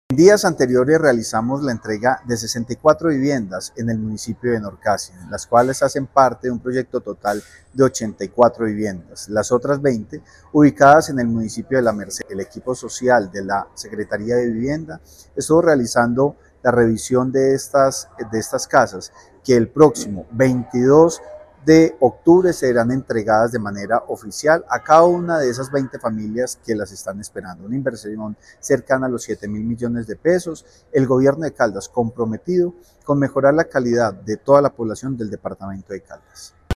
Francisco Javier Vélez Quiroga, secretario de Vivienda y Territorio de Caldas